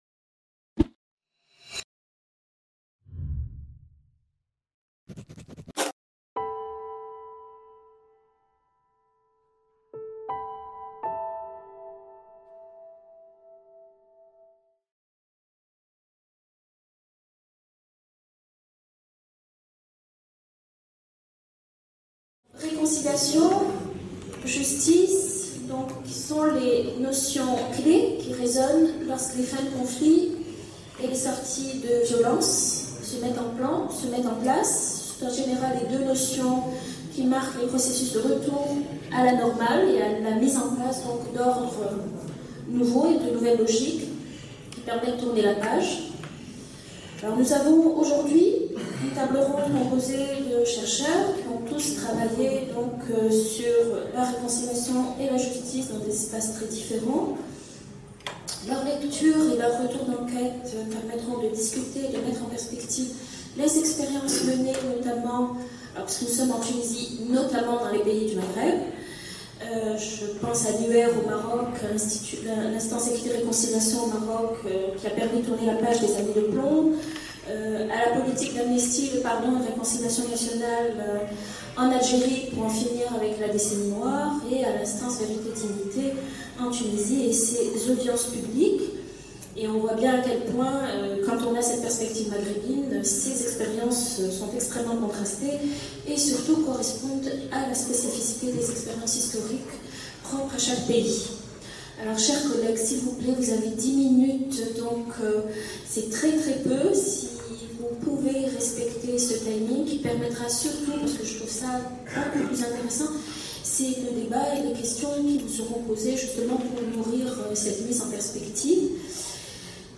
Réconciliation et justice : leçons comparatives - IPEV - Colloque de Tunis | Canal U